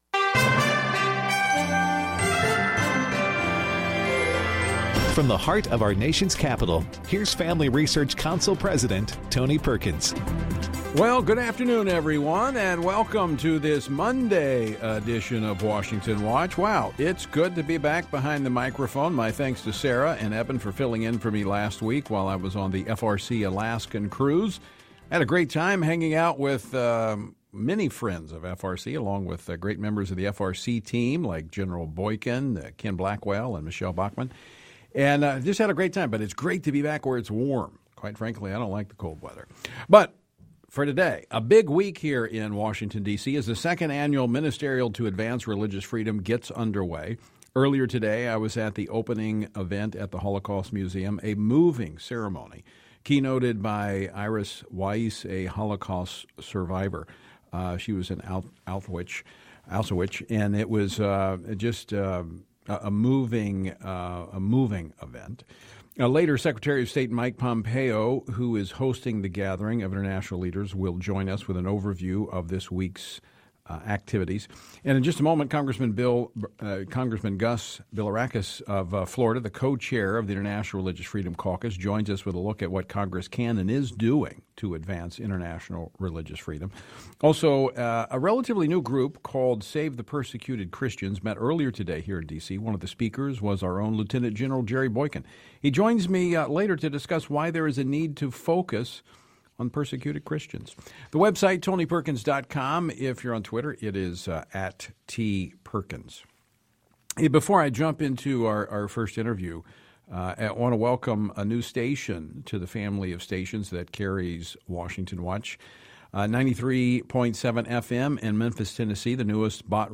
On the Monday, July 15, 2019 edition of Washington Watch with Tony Perkins, we heard from: Gus Bilirakis, U.S. Representative for the 12th District of Florida and Co-chair of the Congressional International Religious Freedom Caucus, to discuss what Congress can do, and is doing, to advance religious freedom around the world. Mike Pompeo, U.S. Secretary of State, to continue the discussion on the U.S. State Department Ministerial to Advance Religious Freedom and to highlight the newly formed Commission on Unalienable Rights, which will advise Secretary Pompeo on how to advocate for the most fundamental rights in diplomatic discussions.